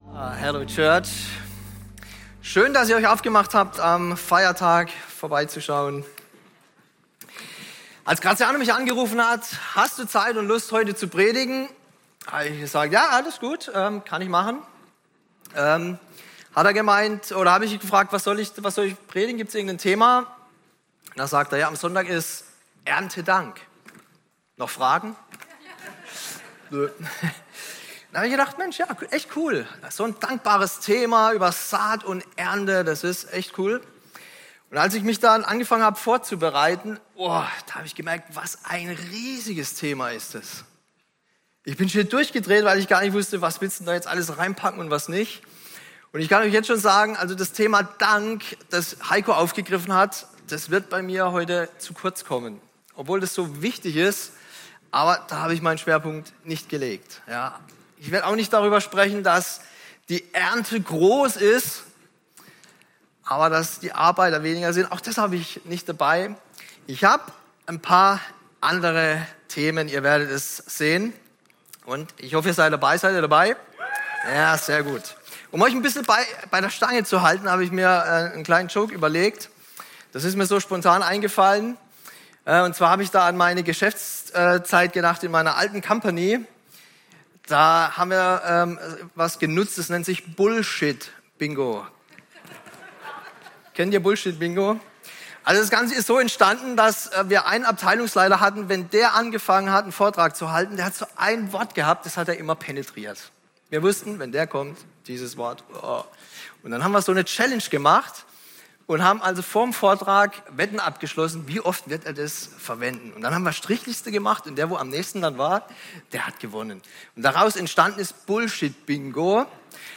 Passage: Galater 6, 7-9 Dienstart: Sonntag